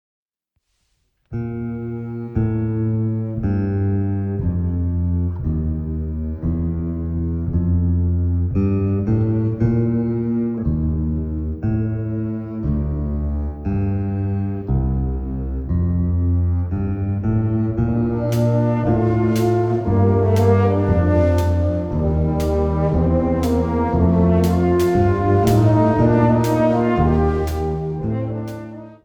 Besetzung Ha (Blasorchester); [ (optional); Rock-Band; ]